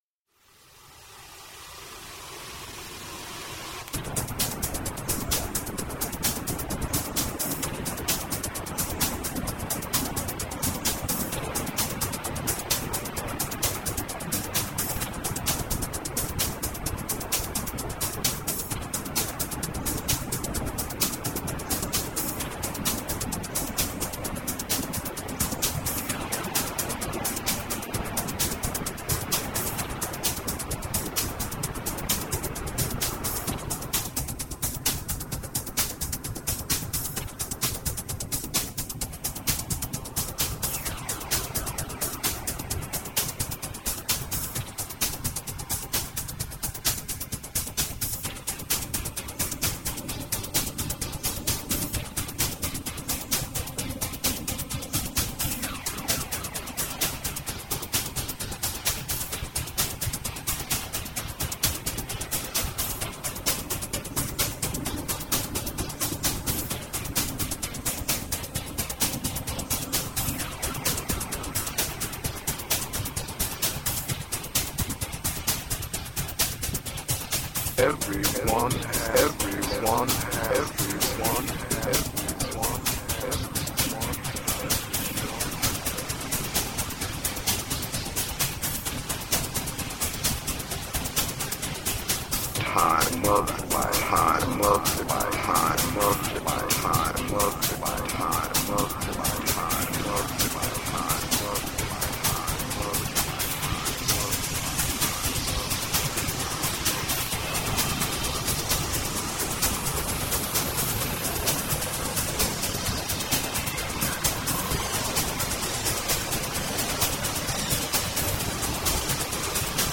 Upbeat underground with shades of new wave.
Tagged as: Electronica, Other, Hard Electronic, IDM